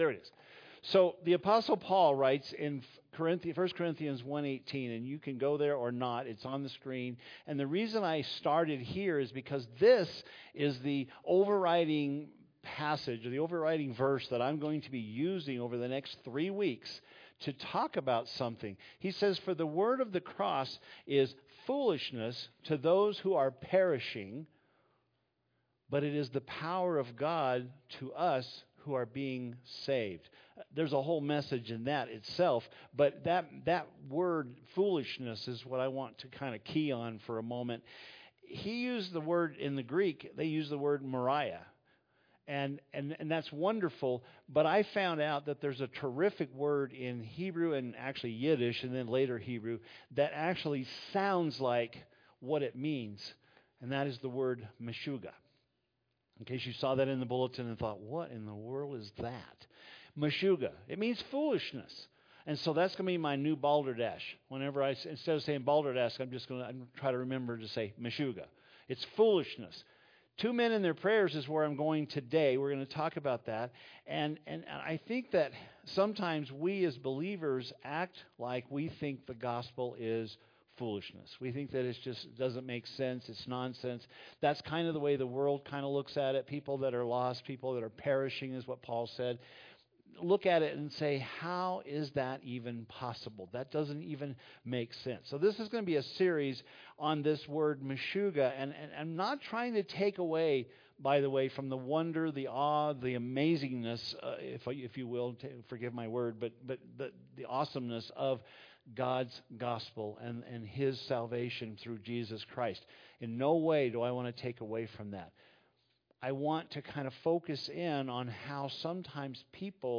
"Meshuggah" it means foolishness. In this sermon we hear two very different prayers and the way two men approached God in their view of themselves, as seen in Luke 18.